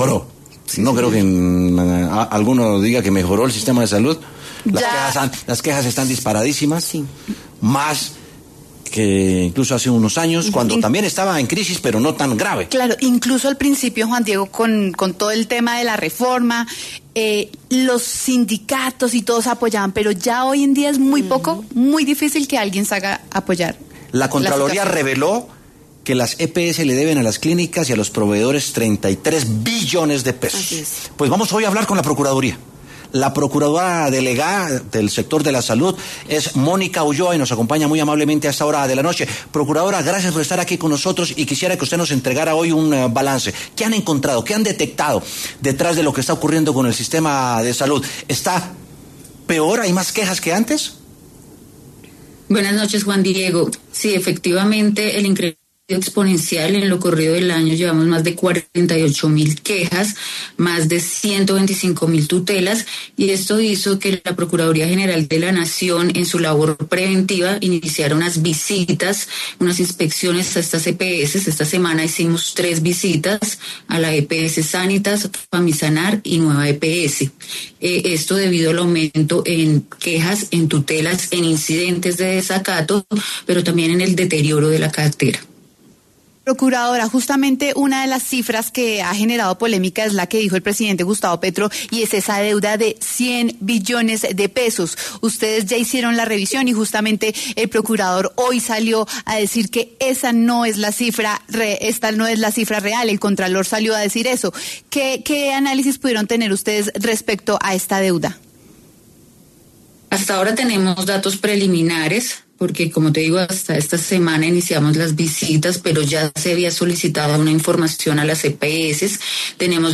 Mónica Ulloa, procuradora delegada para la Salud, pasó por los micrófonos por W Sin Carreta, luego de conocerse, desde la Contraloría, que las EPS le deben a las clínicas y proveedores 33 billones de pesos.